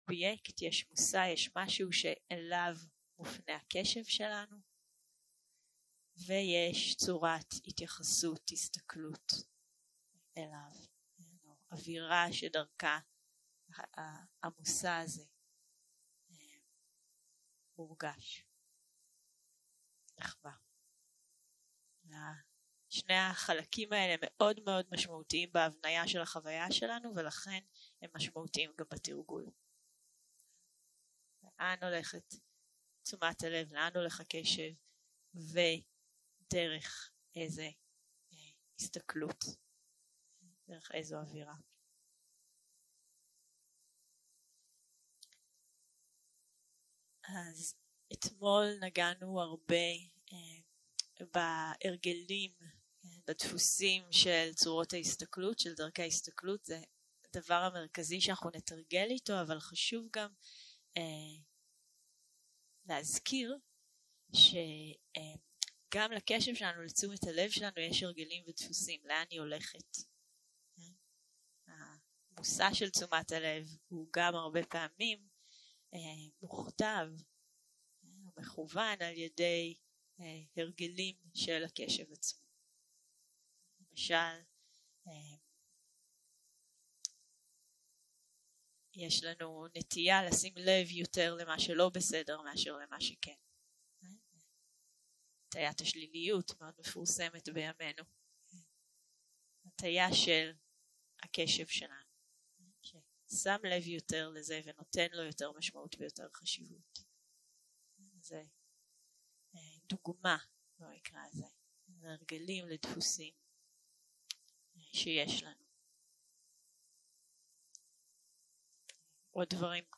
יום 3 - הקלטה 7 - בוקר - הנחיות למדיטציה - עבודה עם כאב ועוד 0:00 0:00 סוג ההקלטה: Dharma type: Guided meditation שפת ההקלטה: Dharma talk language: Hebrew